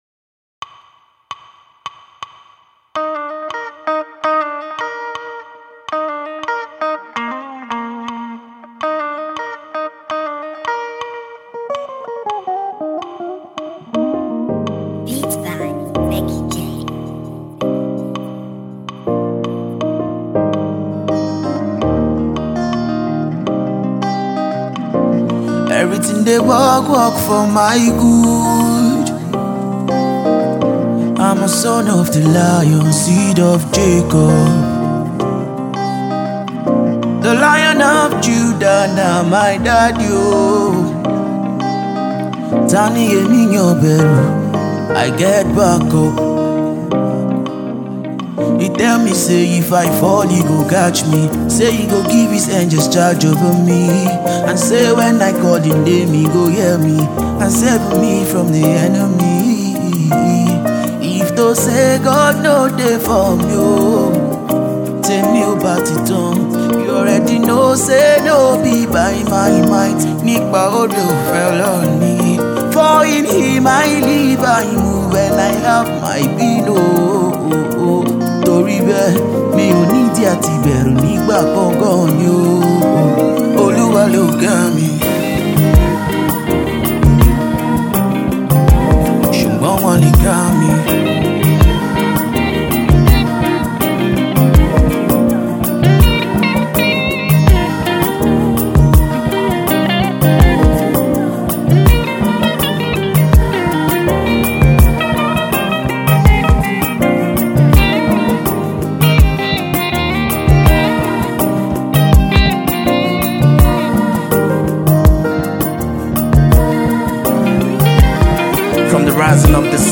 Gospel urban Fuji/High-life music